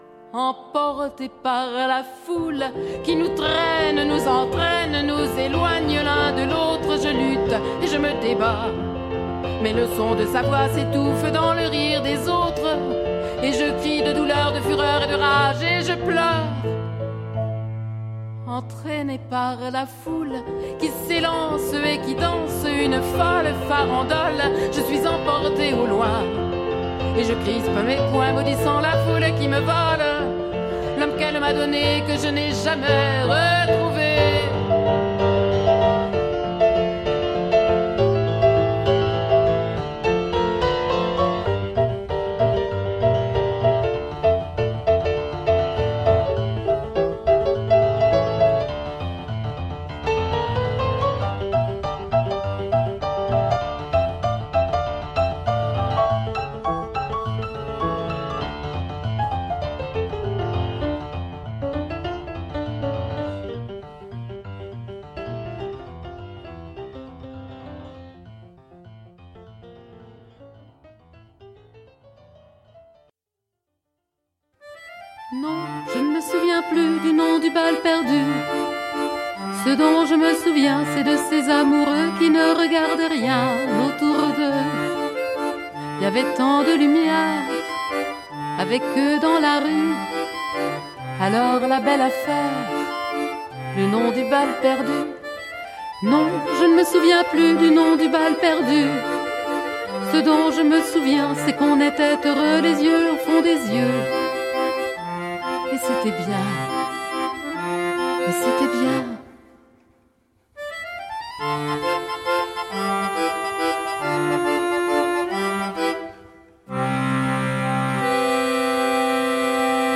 chanson française
accompagnée à l'orgue de barbarie ou au piano